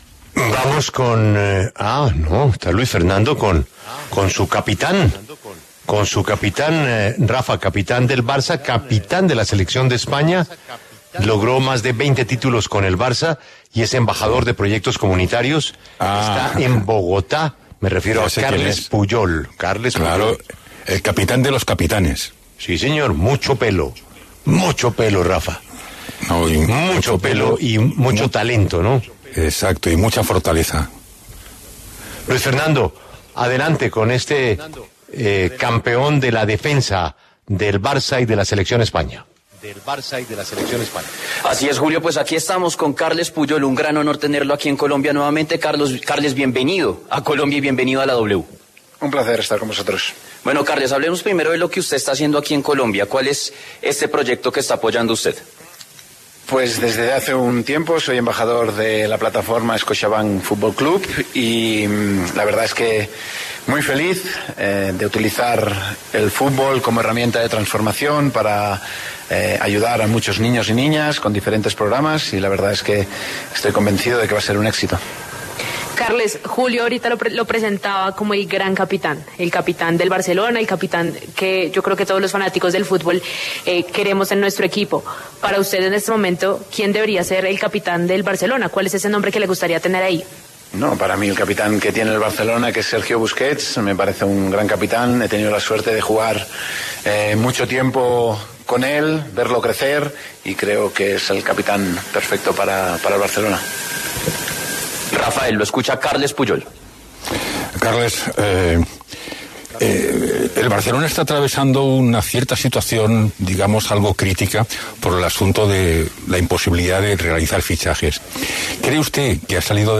Carles Puyol, exfutbolista español y leyenda del FC Barcelona, habló en La W sobre su visita a Colombia por el lanzamiento del Scotiabank Fútbol Club.
En el encabezado escuche la entrevista completa con Carles Puyol, exfutbolista español y leyenda del FC Barcelona.